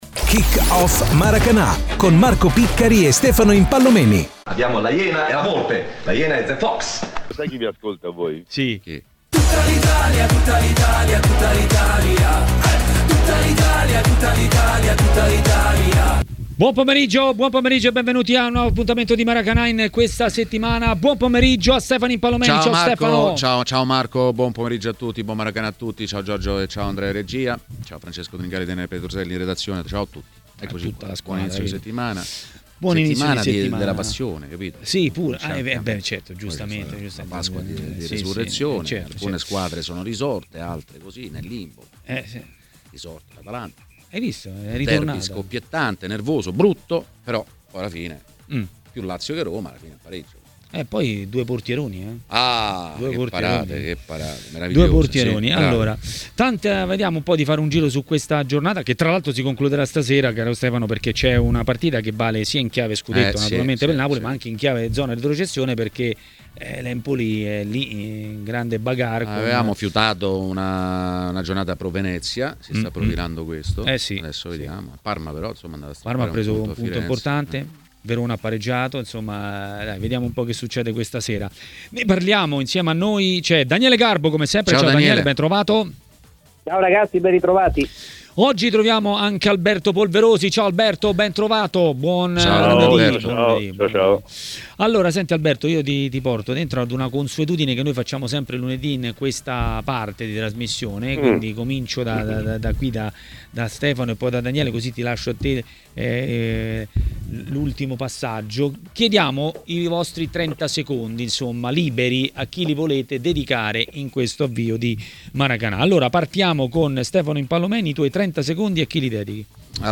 è stato ospite di Maracanà, trasmissione di TMW Radio.